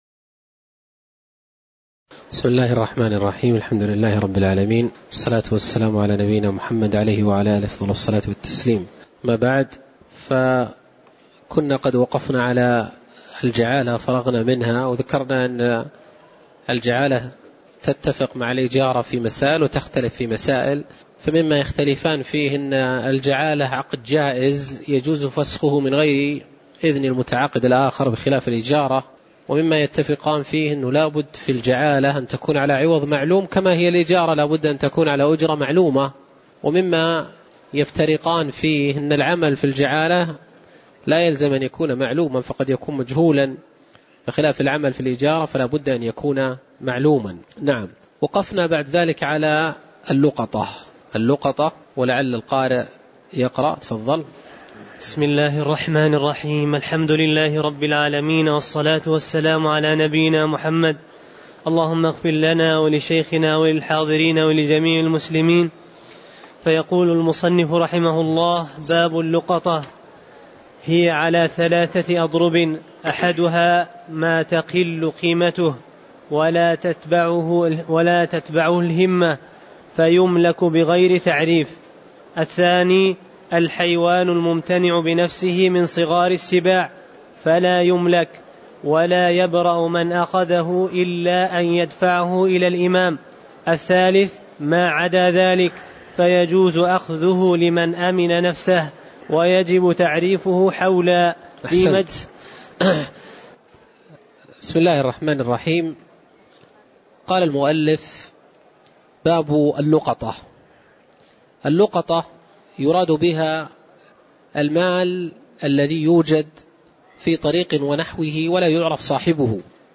تاريخ النشر ١٩ شوال ١٤٣٩ هـ المكان: المسجد النبوي الشيخ